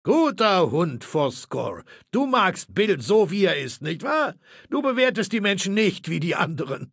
Datei:Maleold01 ms06 hello 000681ba.ogg
Fallout 3: Audiodialoge